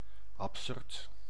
Ääntäminen
France: IPA: [a.be.ʁɑ̃]